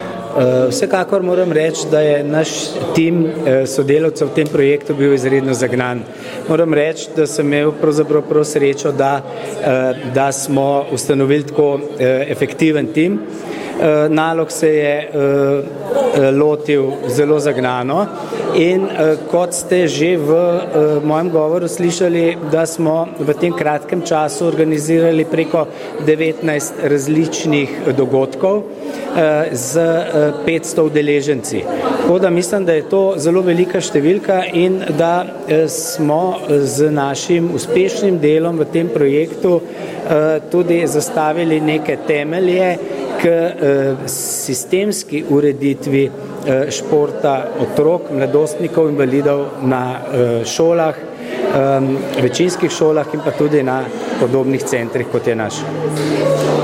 Avdio izjave: